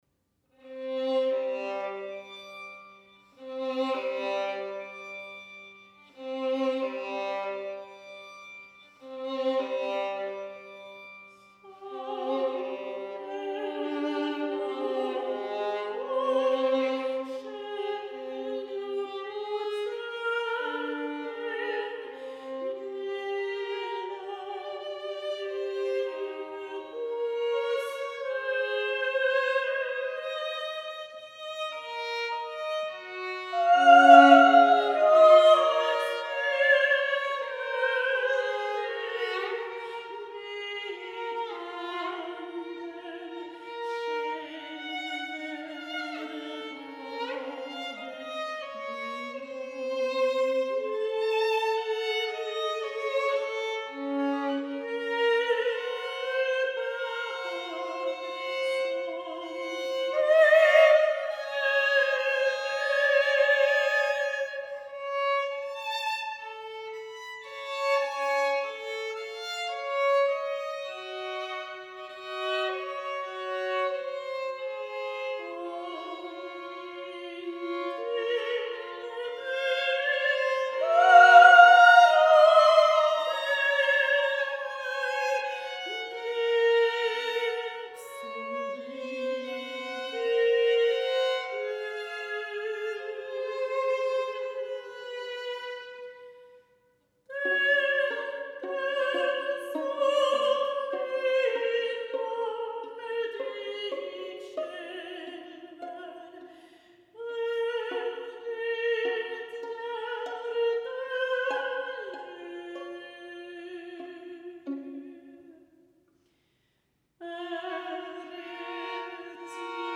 Mezzosopran
Violin